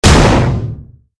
LB_gavel.ogg